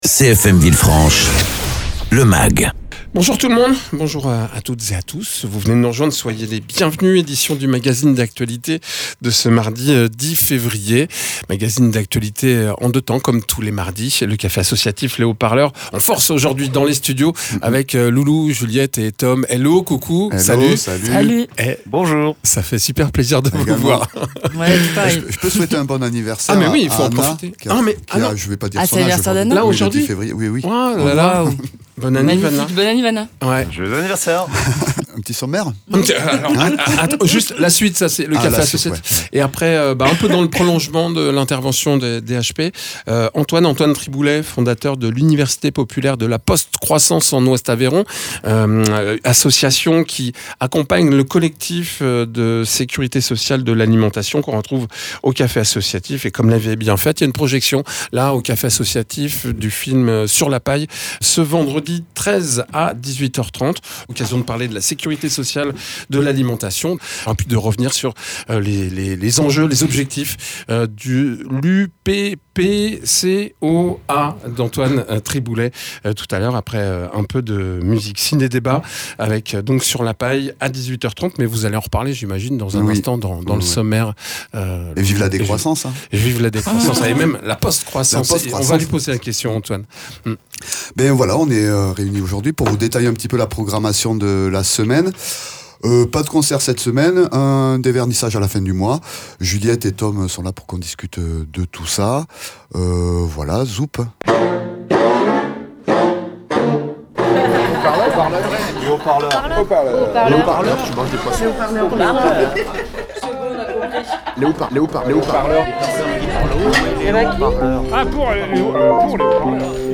membres du café associatif Les Hauts Parleurs